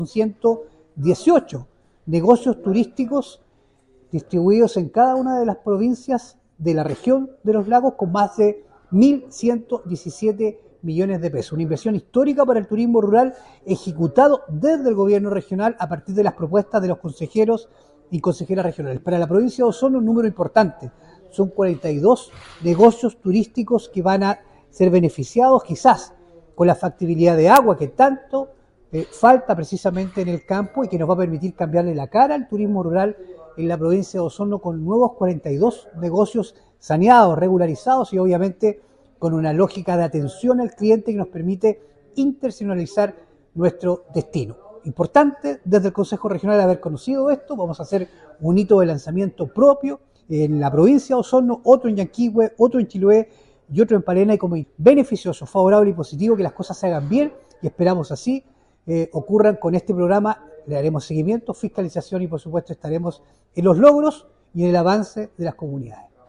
Francisco Reyes, consejero regional de la provincia de Osorno, comentó que gracias a este proyecto son 118 negocios turísticos distribuidos en cada una de las provincias de la región de Los Lagos que se verán beneficiados con una inversión total de 1.117 millones de pesos, una cifra histórica para el turismo rural ejecutado desde el Gobierno Regional.